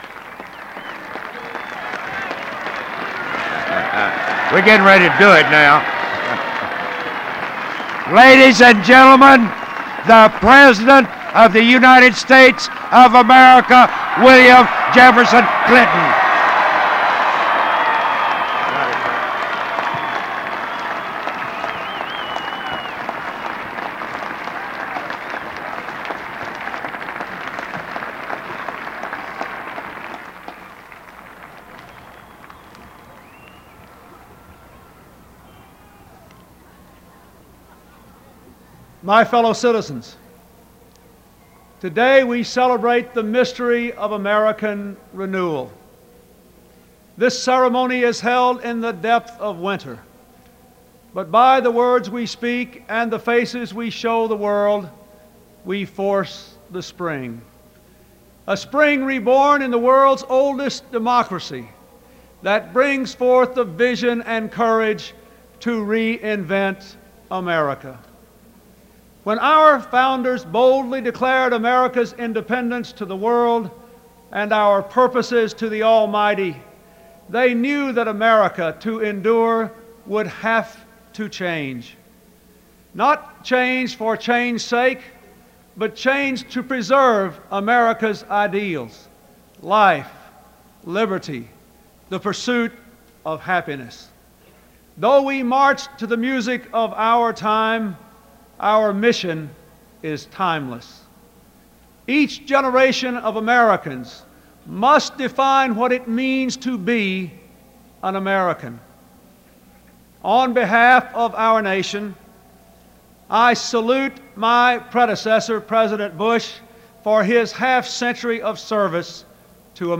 Bill Clinton: First Inaugural Address by Bill Clinton on Free Audio Download
Renewal and reform were the prevalent themes of Bill Clinton's first inaugural address in 1993.
BillClintonFirstInauguralAddress.mp3